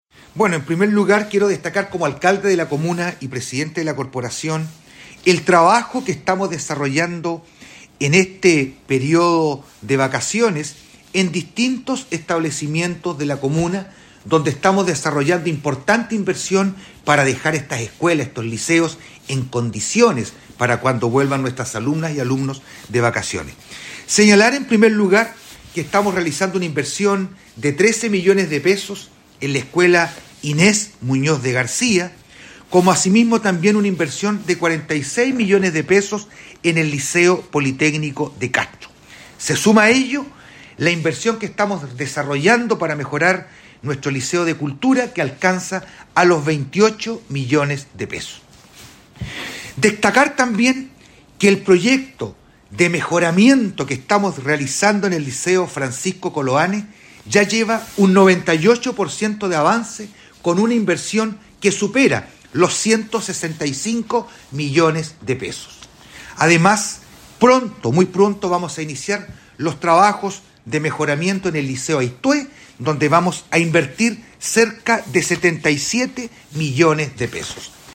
Al respecto, el jefe comunal, señaló.